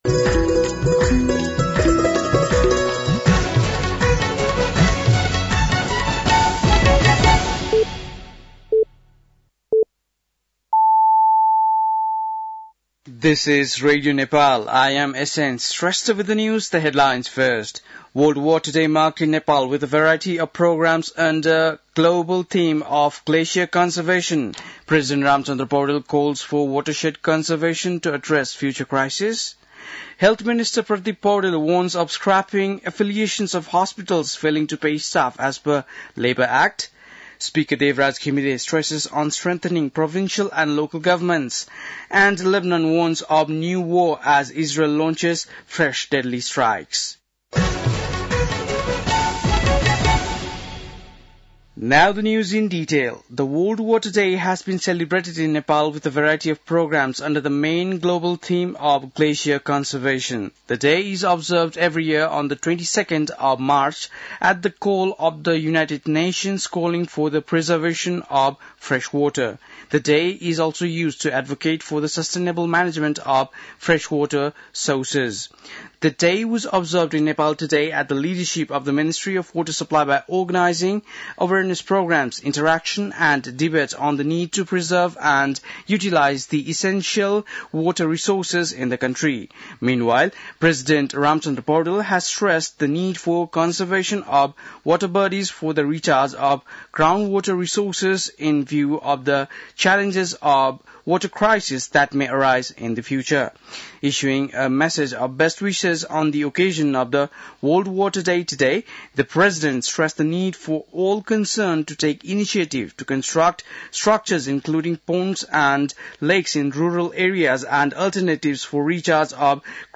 बेलुकी ८ बजेको अङ्ग्रेजी समाचार : ९ चैत , २०८१